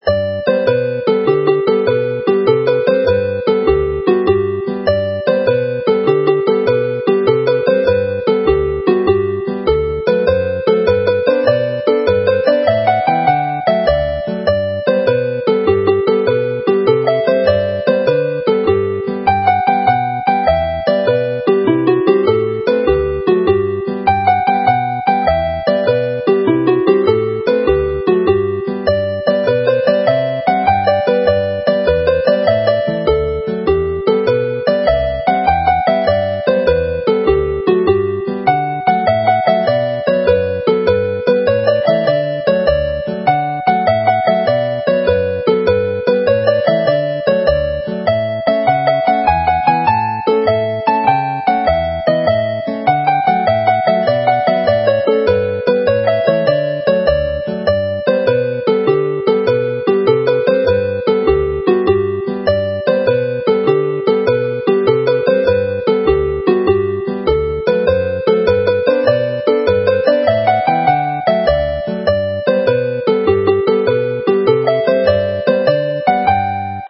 This set of jigs is a direct adaptation into 6/8 time of the December 2011 Nos Galan set based on perhaps the most famous Christmas melody of all: Nos Galan, with Glanbargoed and Llwytcoed as supporting tunes in the dance set.